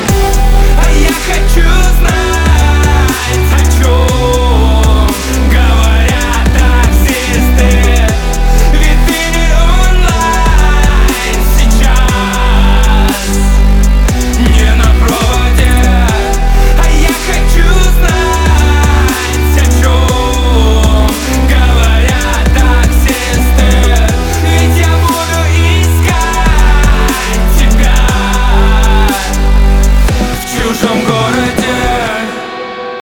• Качество: 320, Stereo
Хип-хоп
alternative hip hop